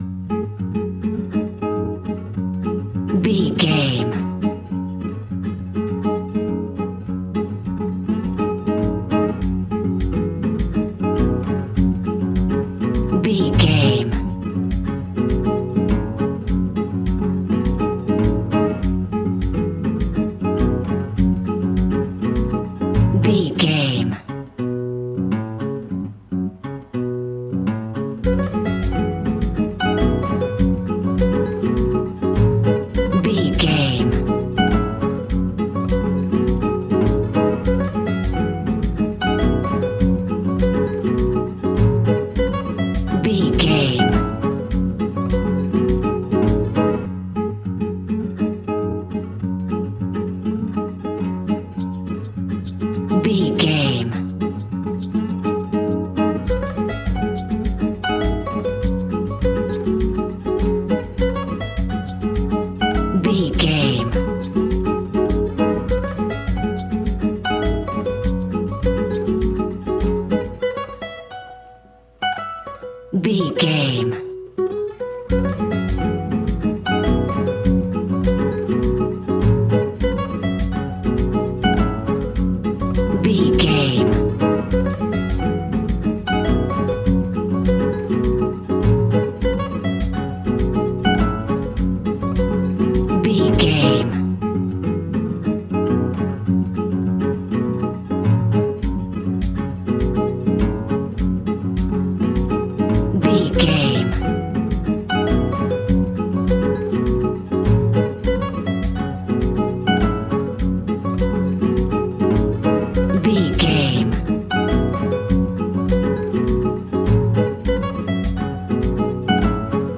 Uplifting
Aeolian/Minor
F#
maracas
percussion spanish guitar